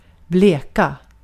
Ääntäminen
IPA : /tu bliːtʃ/